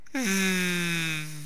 bee1.wav